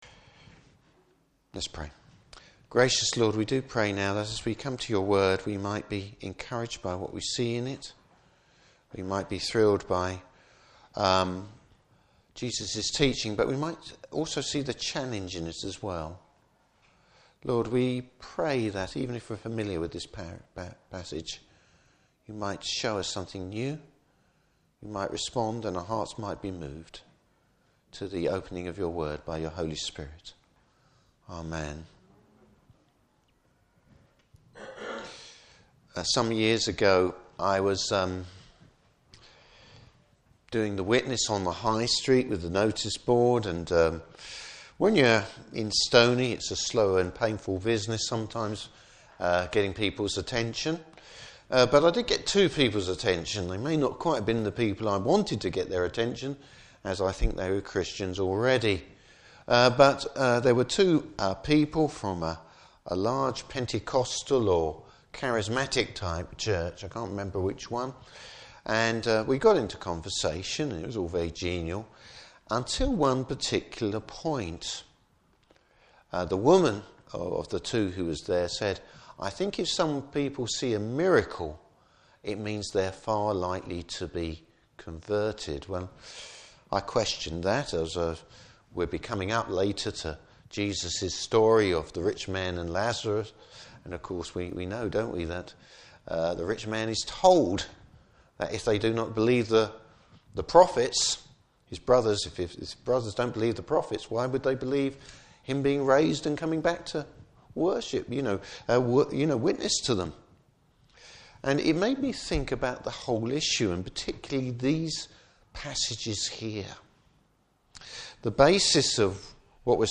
Service Type: Morning Service Bible Text: Luke 11:29-32.